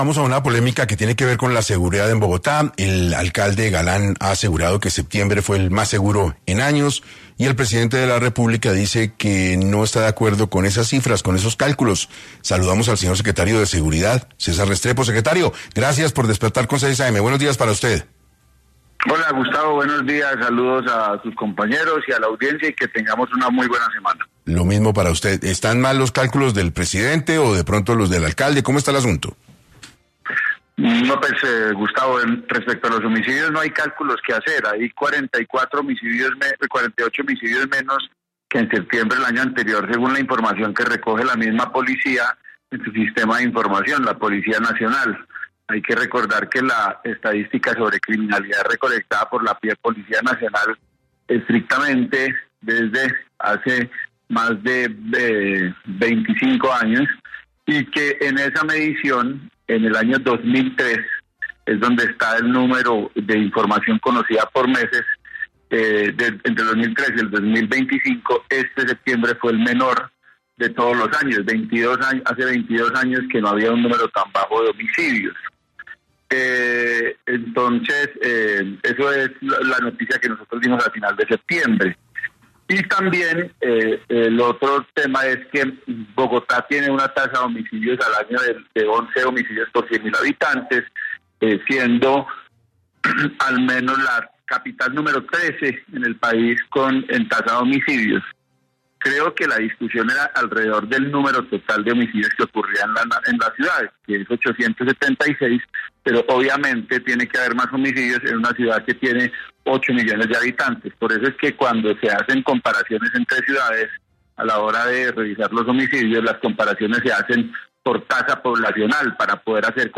César Restrepo, secretario de Seguridad de Bogotá afirmó en 6AM que la tasa de homicidios en la capital disminuyó un 40% frente a septiembre del año pasado